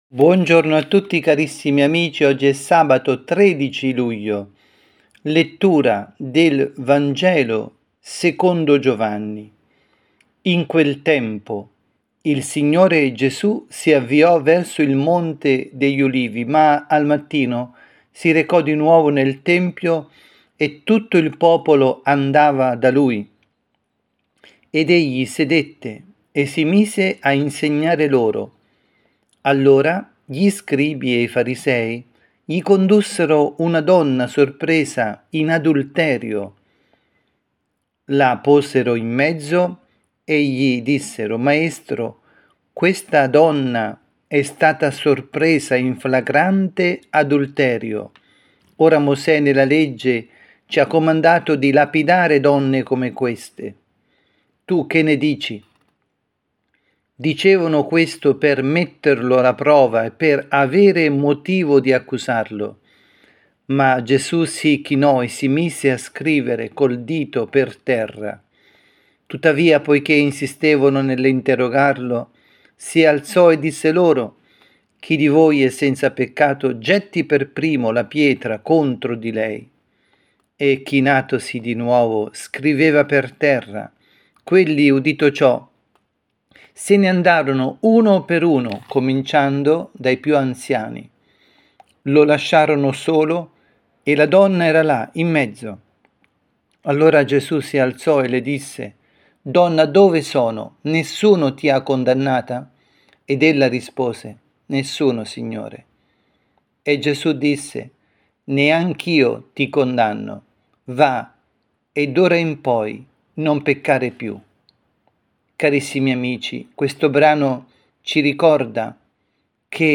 Catechesi
dalla Parrocchia Santa Rita – Milano